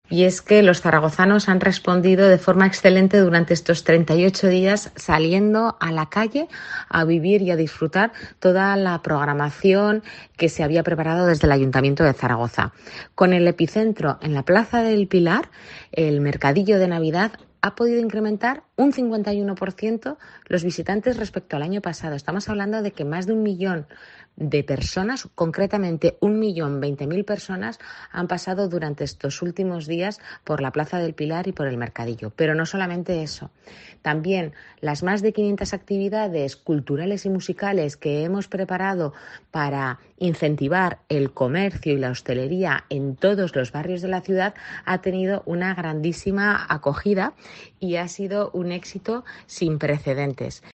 La alcaldesa de Zaragoza, Natalia Chueca, hace balance de estas fiestas navideñas en la capital